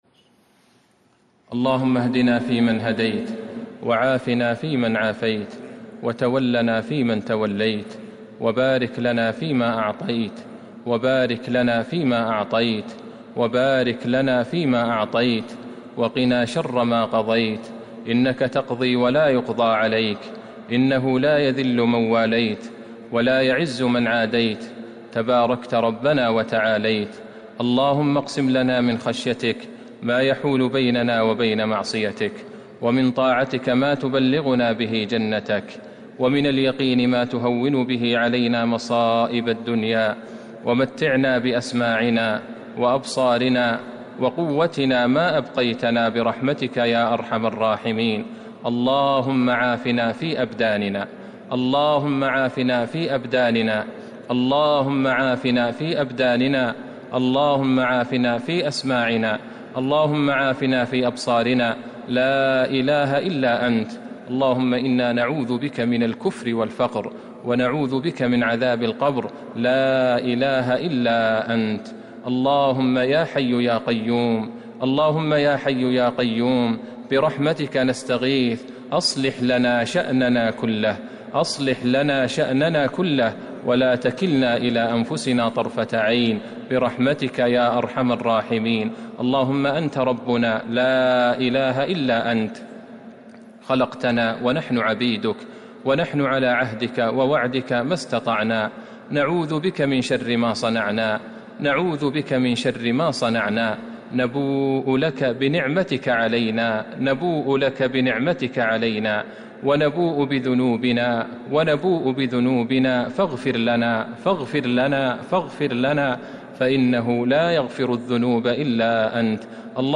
دعاء القنوت ليلة 28 رمضان 1441هـ > تراويح الحرم النبوي عام 1441 🕌 > التراويح - تلاوات الحرمين